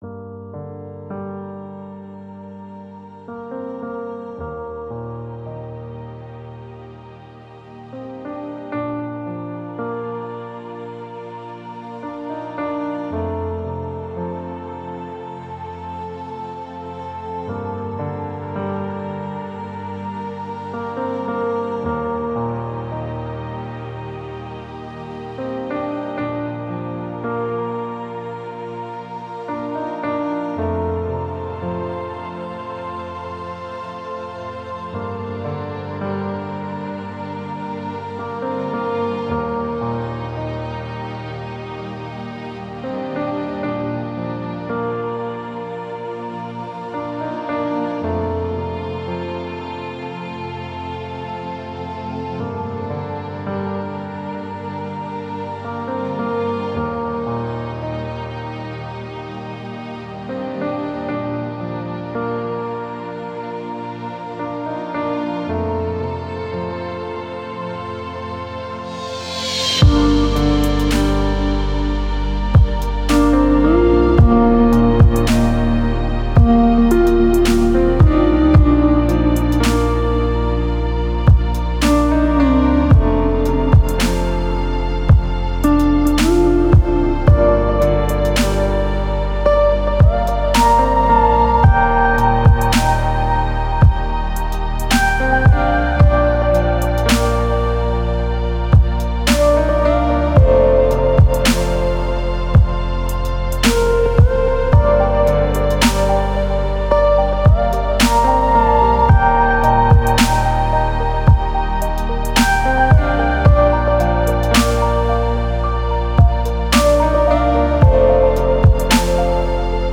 это трек в жанре альтернативного рока